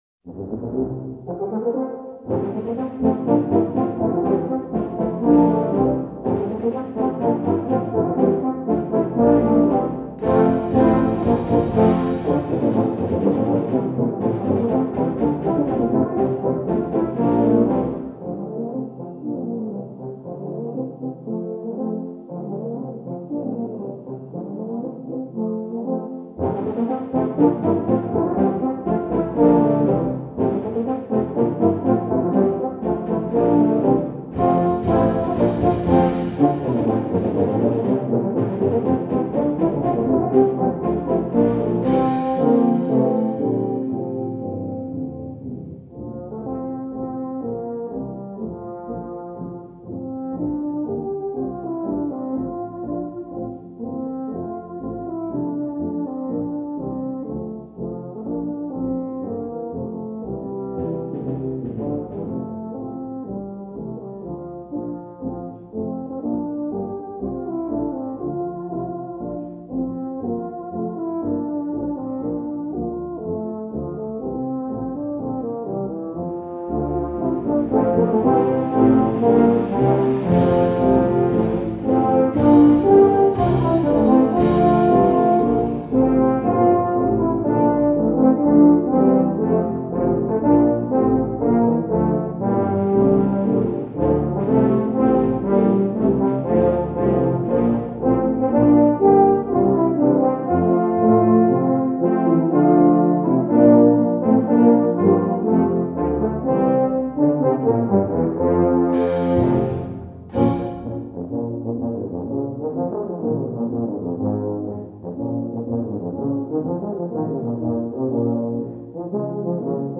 For Tuba Ensemble
3 Euph and 3 Tbas.